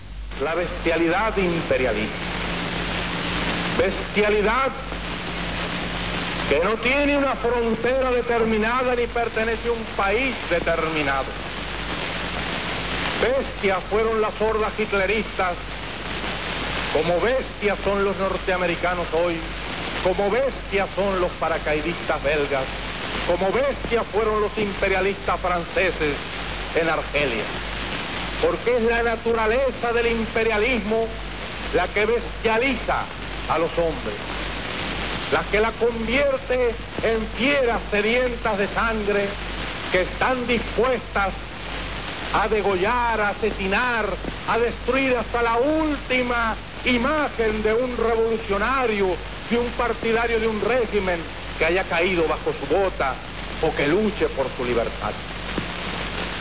Esta sección esá hecha a partir de pequeños trozos de discursos.